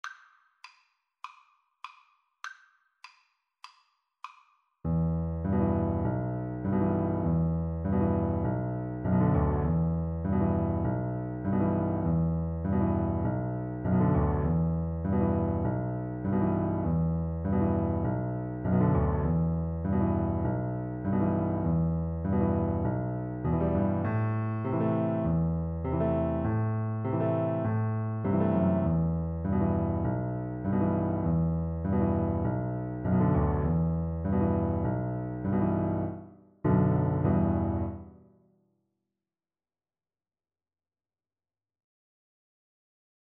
Free Sheet music for Piano Four Hands (Piano Duet)
Moderato
C major (Sounding Pitch) (View more C major Music for Piano Duet )